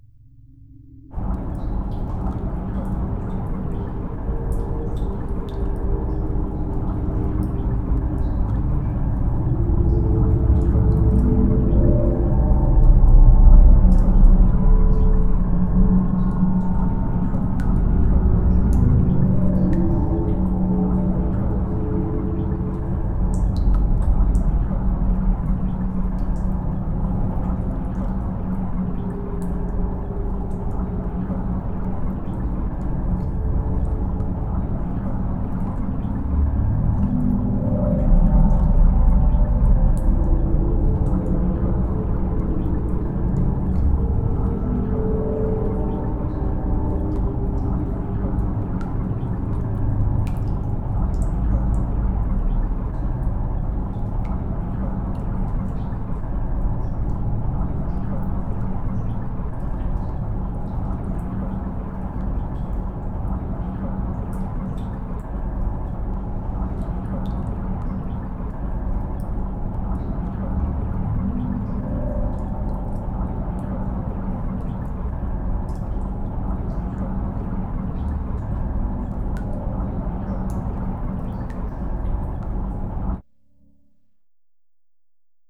cave_ambience.L.wav